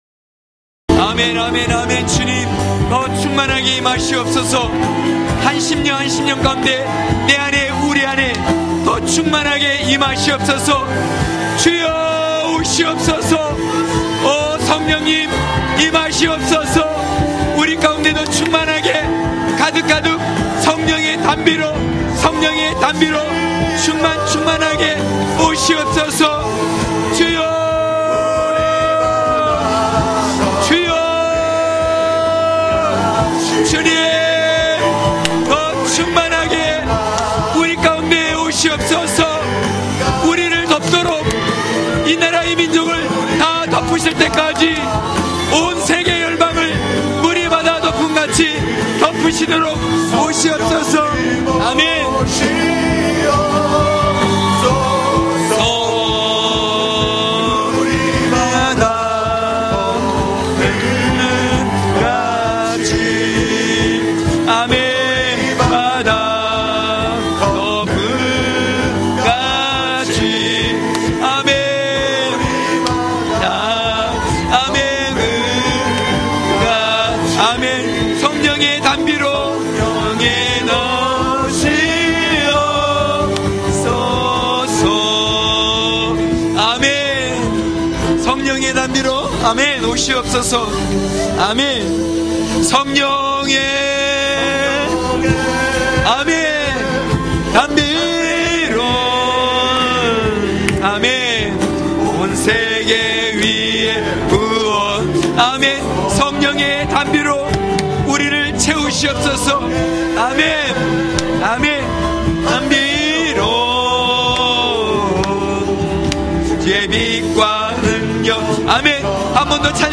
강해설교 - 19.예루살렘 사람들..(느11장1~6절).mp3